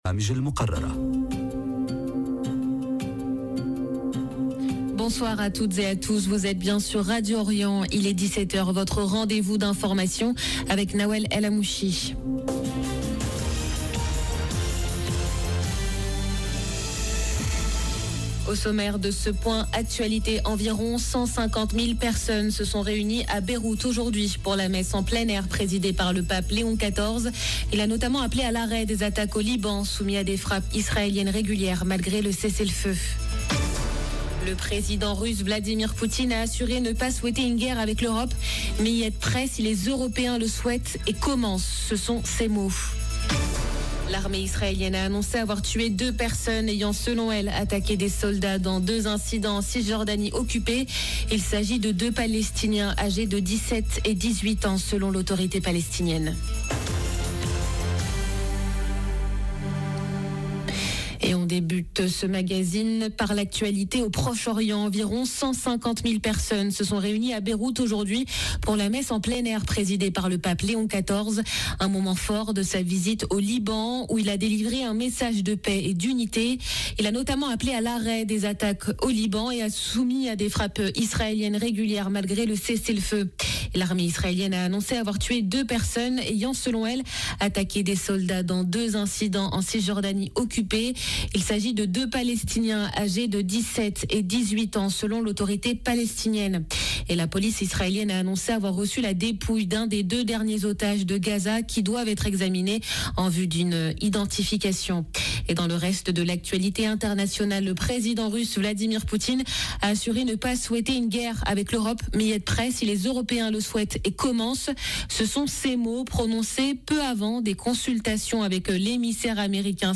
JOURNAL DE 17H Au sommaire: Environ 150.000 personnes se sont réunies aujourd’hui à Beyrouth pour la messe en plein air présidée par le pape Léon XIV.